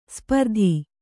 ♪ spardhi